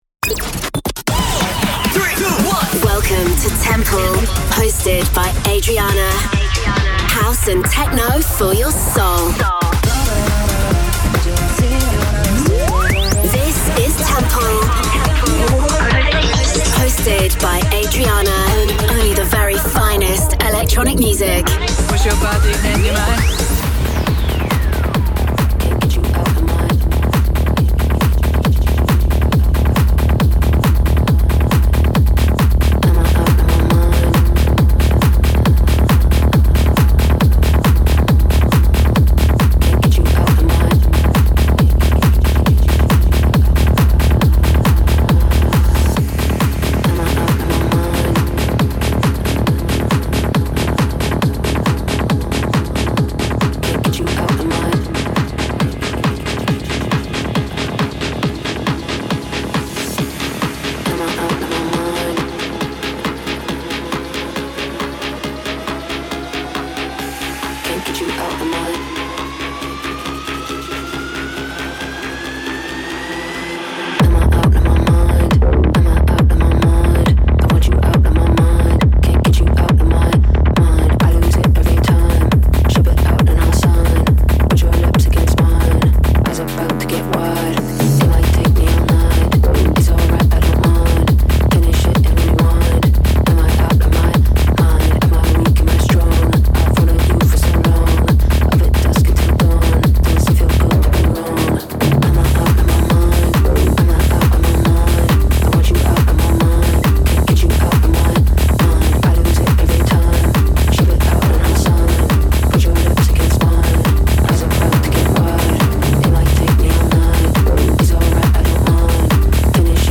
Live Set